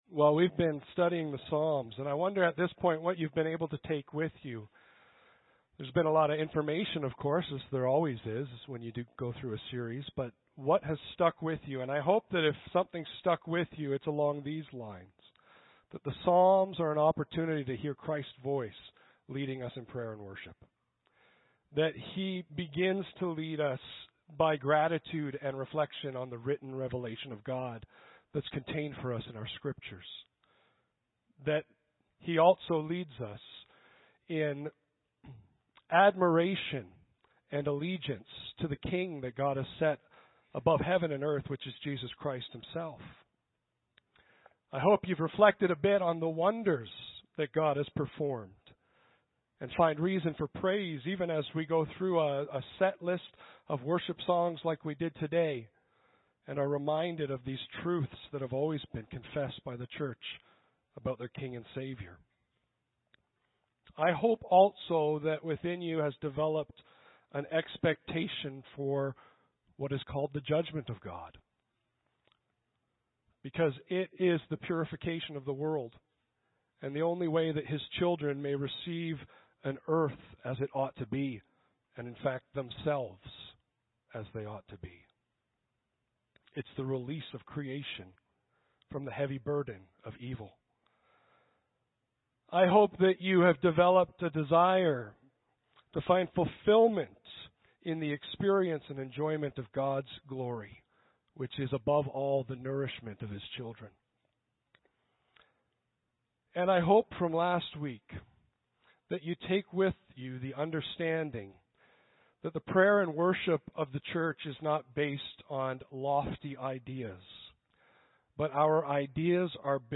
Audio Sermons - Clive Baptist Church